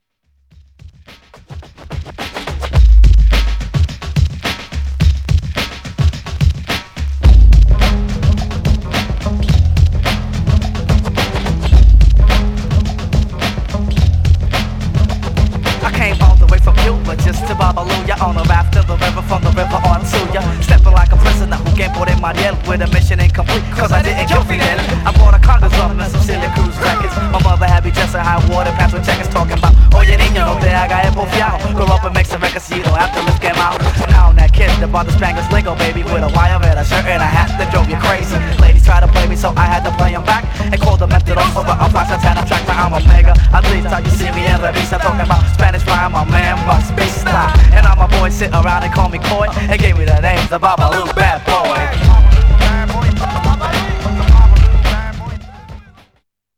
Styl: Hip Hop